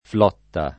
flotta
[ fl 0 tta ]